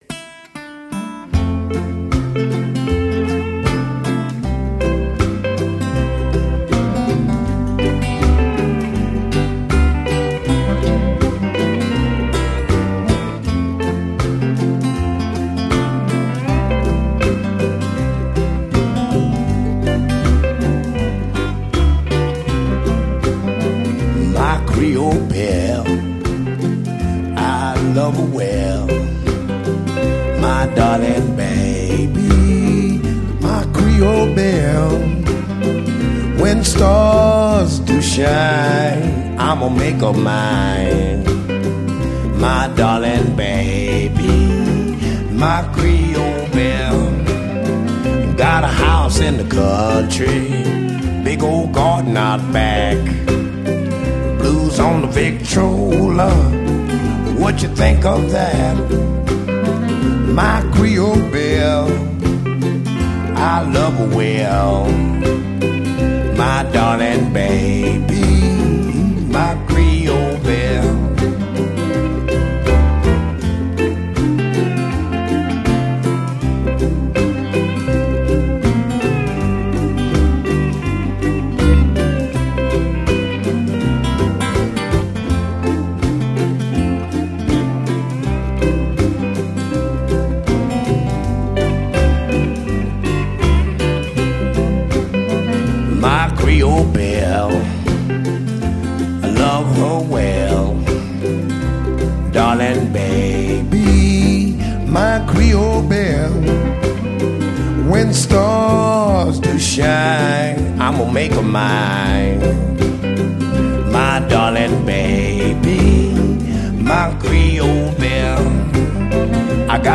kontrabas
bobni
ukulele
havajska kitara
saksofon, kalimba, flavta, klarinet